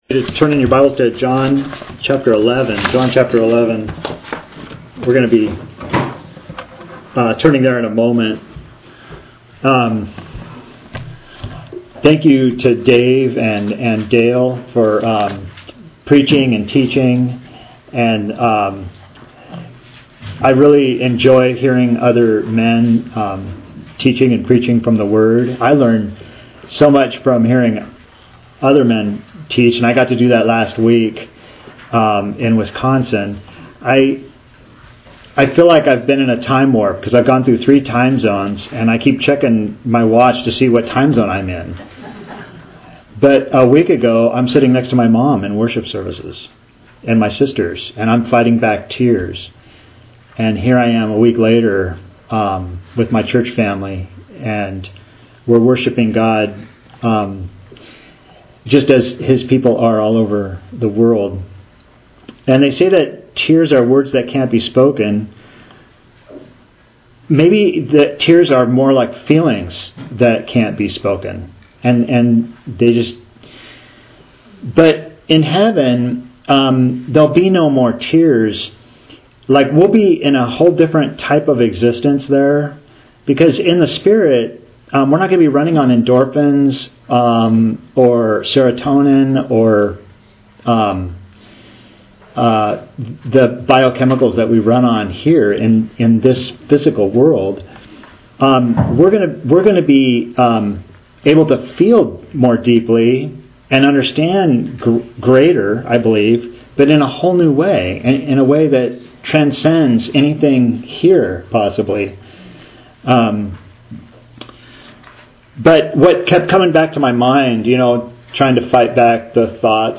Author jstchurchofchrist Posted on March 5, 2026 March 5, 2026 Categories Sermons Tags Jesus , resurrection